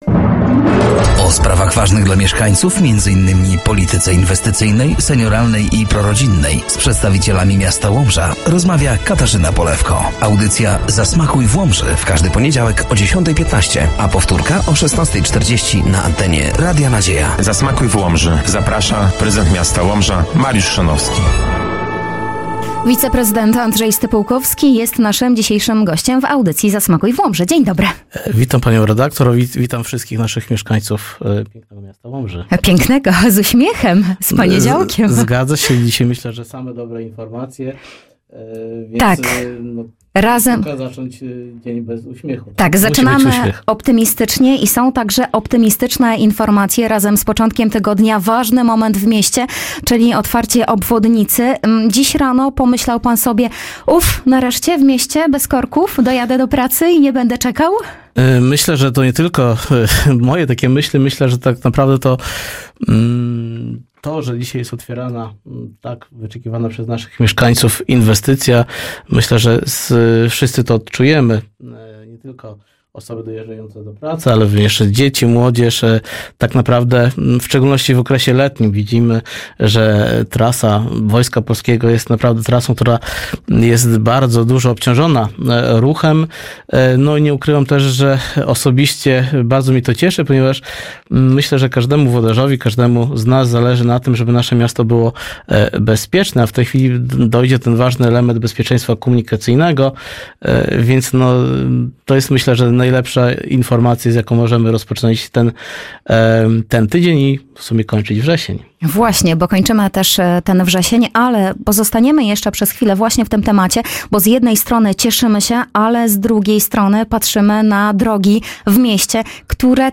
Studio Radia Nadzieja odwiedził wiceprezydent miasta Andrzej Stypułkowski.